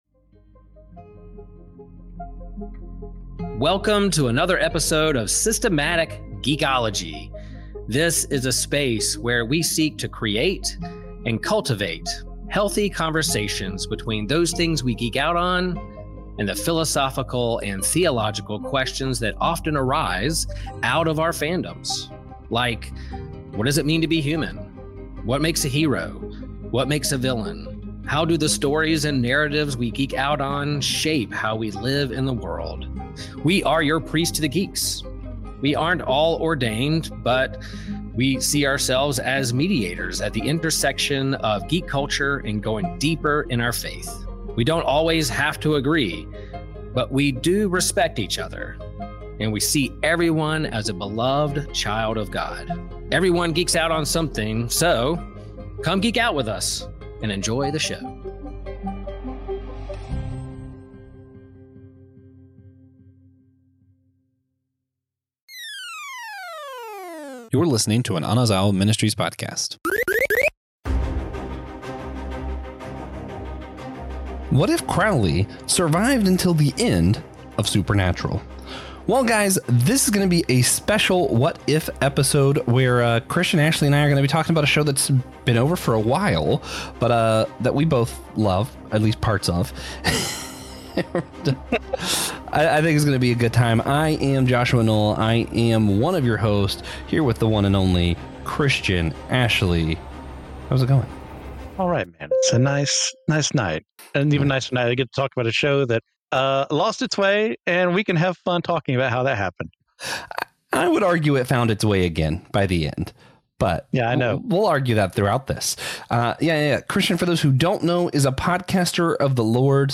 They kick things off by reminiscing about the show's ups and downs, all while keeping it chill and full of banter.
The episode kicks off with a casual and humorous exchange, as the trio reflects on their current geeky obsessions, from video games to anime, setting a light-hearted tone that invites listeners to join in on the fun.